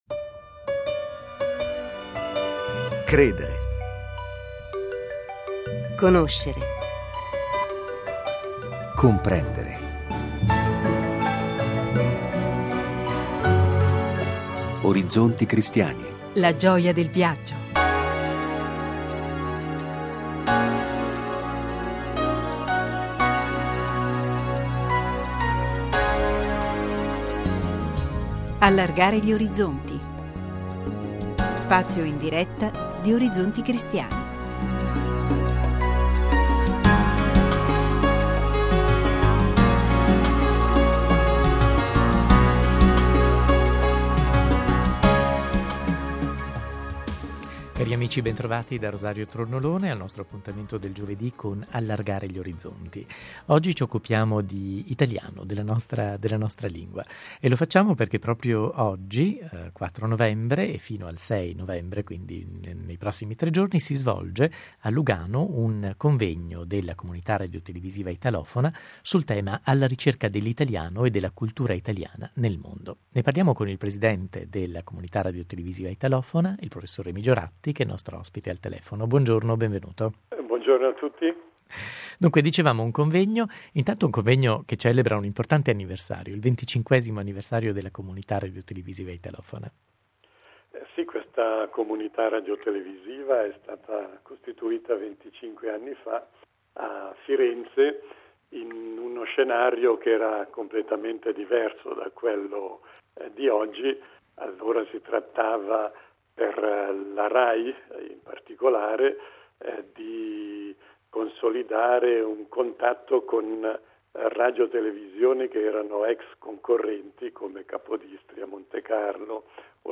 ospite del convegno in collegamento telefonico da Nerw York.